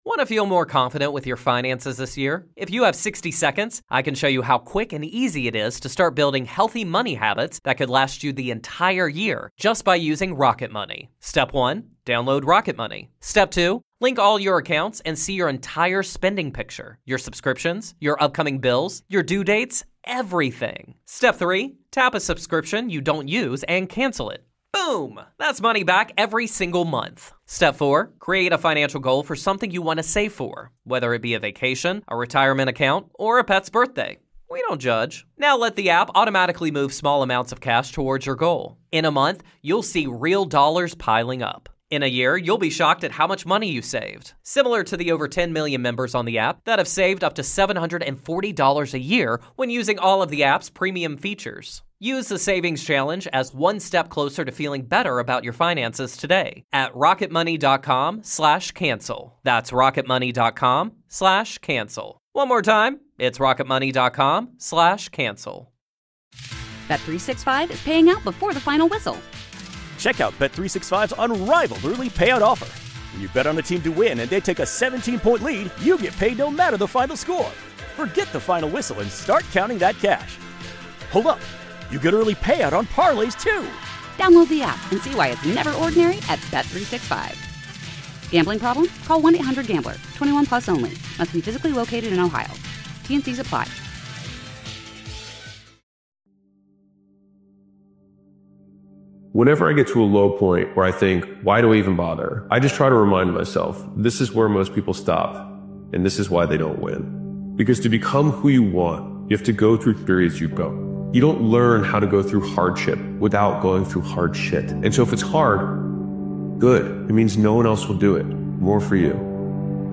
Powerful Motivational Speech Video is a raw and atmospheric motivational video created and edited by Daily Motivation.